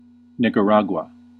Ääntäminen
Ääntäminen US UK : IPA : /ˌnɪk.ə.ˈɹæɡ.ju.ə/ US : IPA : /ˌnɪk.ə.ˈɹɑ.ɡwə/ Lyhenteet ja supistumat (laki) Nicar.